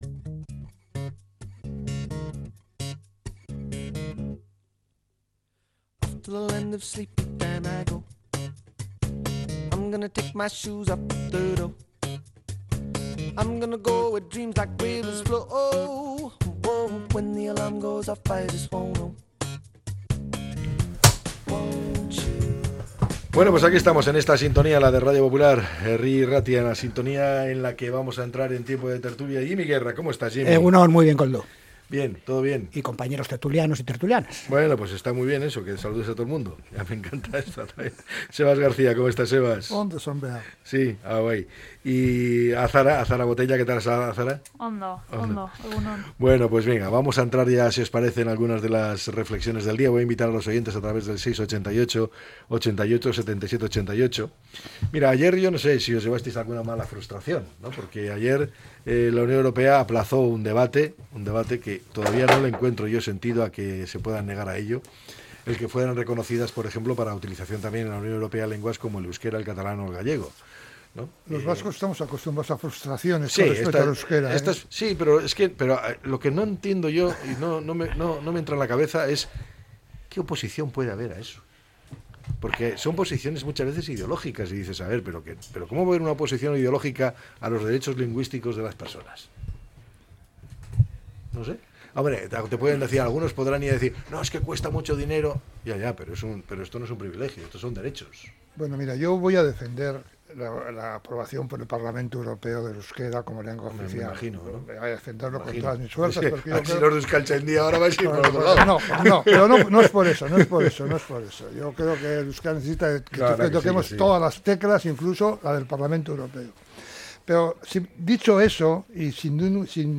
La tertulia 28-05-25.